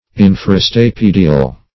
Search Result for " infrastapedial" : The Collaborative International Dictionary of English v.0.48: Infrastapedial \In`fra*sta*pe"di*al\, a. [Infra + stapedial.]